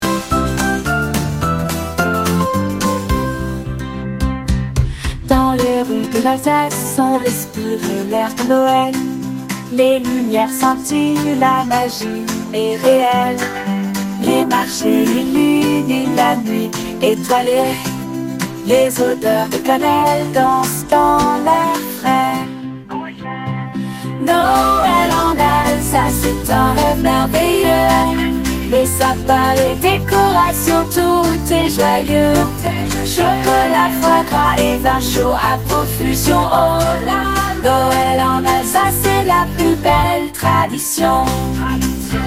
Bien que la voix paraisse légèrement robotique (ou auto-tunée), l’outil réussit à créer une chanson très convaincante, intégrant des paroles adaptées, des rimes, une sonorité typique des chansons de Noël, et même des chœurs !